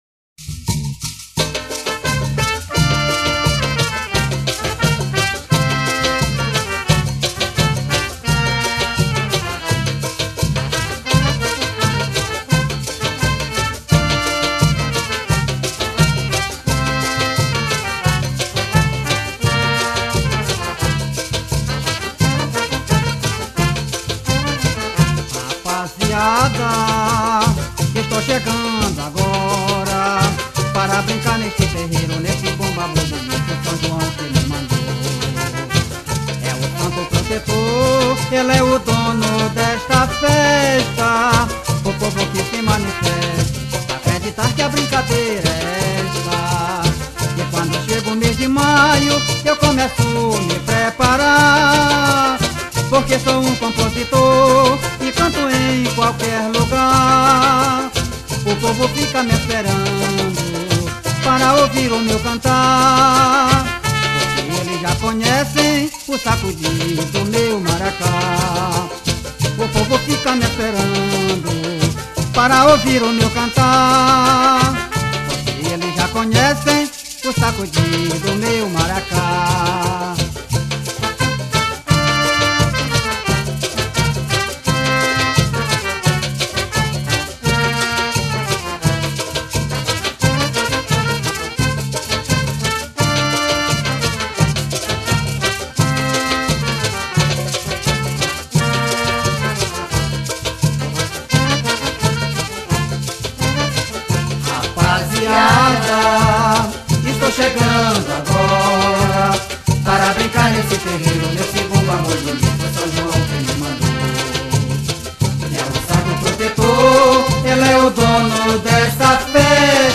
sotaqueboi.mp3